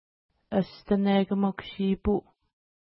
Pronunciation: əstnekəmuk-ʃi:pu: